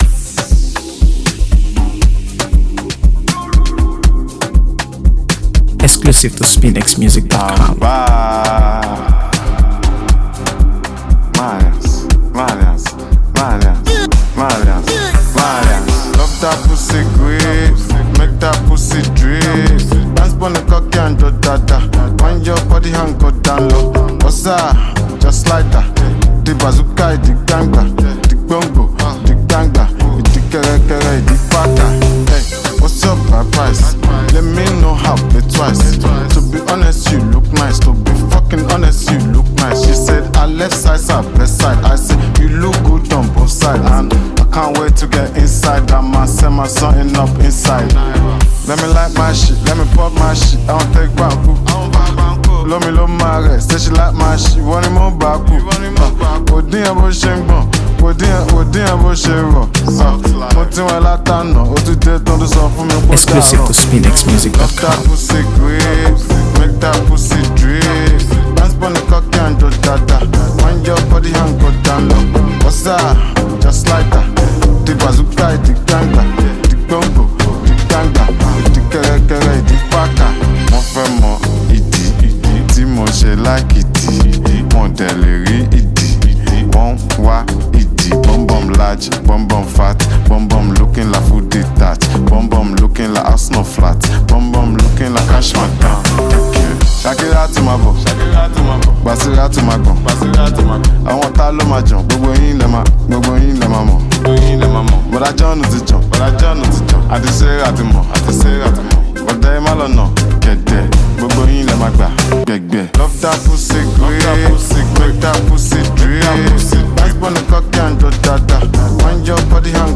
AfroBeats | AfroBeats songs
blending infectious rhythms with his unmistakable style.